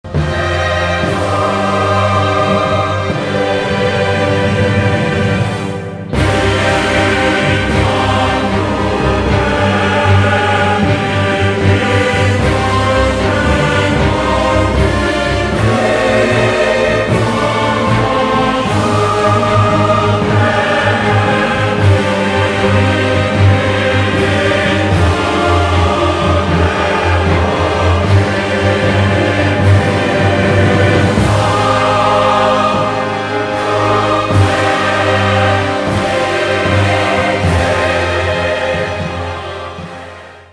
• Качество: 192, Stereo
инструментальные
OST
хор